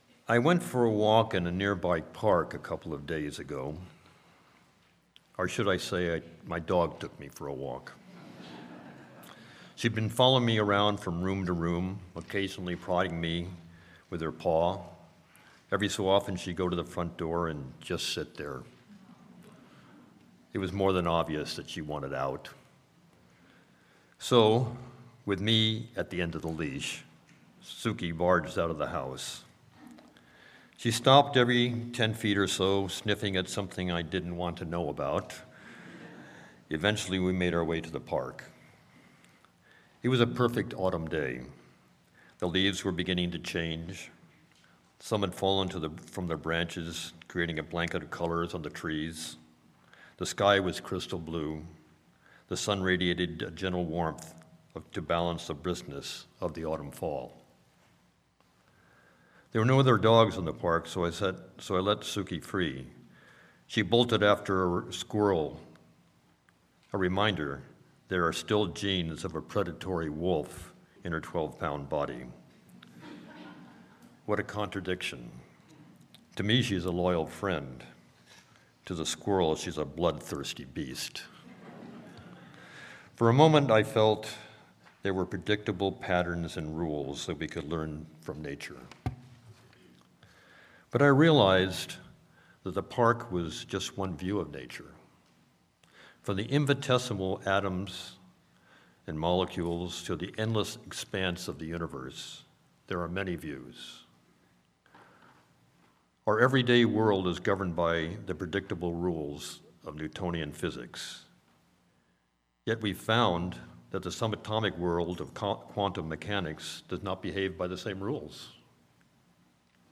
Sermon-Patterns-in-Nature.mp3